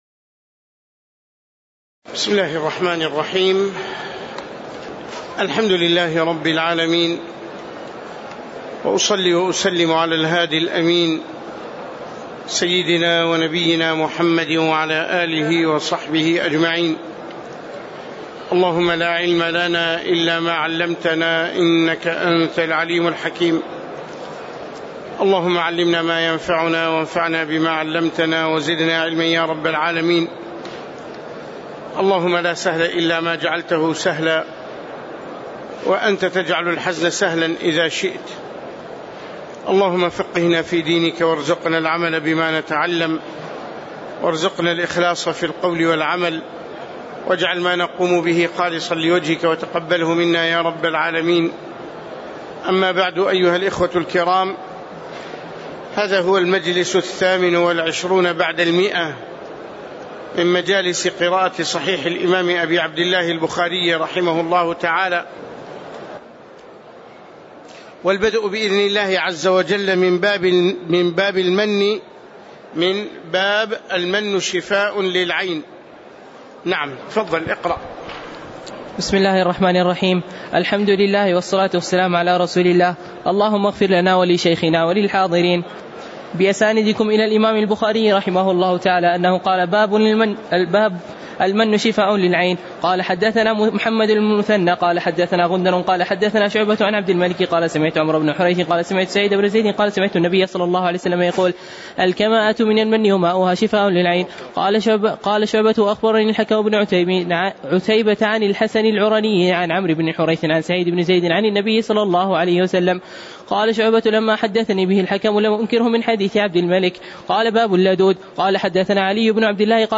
تاريخ النشر ٢ رمضان ١٤٣٨ هـ المكان: المسجد النبوي الشيخ